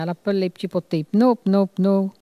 Elle crie pour appeler les canetons